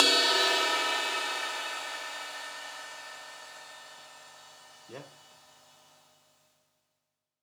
Crash processed 8.wav